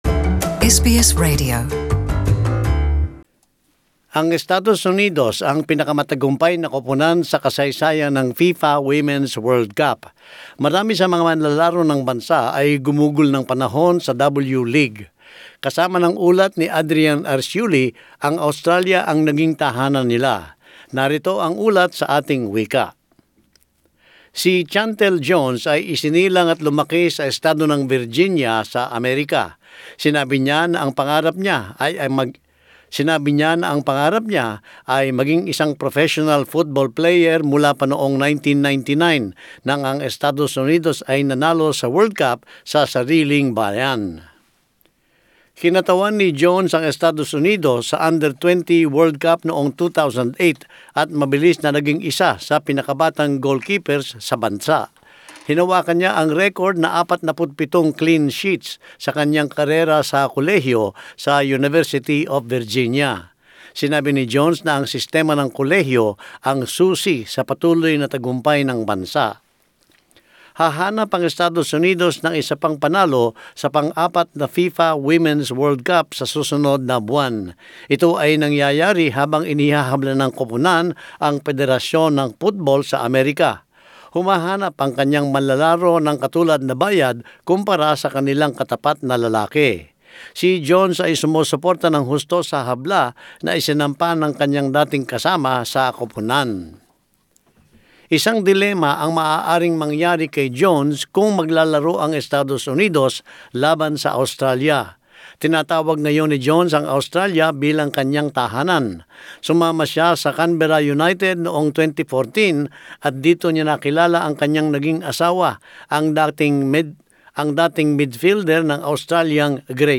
And as this report shows, Australia has become their home.